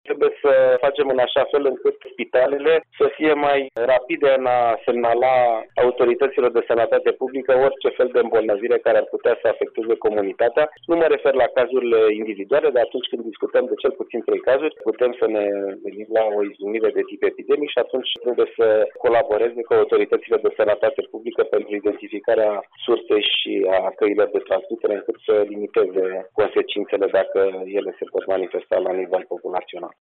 Preşedintele Societăţii Române de Microbiologie, doctorul Alexandru Rafila a anunţat, la Radio România Actualităţi, că este posibil ca sursa infecţiilor să nu fie identificată, ținând seama că probele de laborator au fost prelevate relativ târziu, iar copiii au primit tratament cu antibiotice.
Medicul Alexandru Rafila a avansat şi o soluţie pentru ca astfel de cazuri să fie gestionate corespunzător: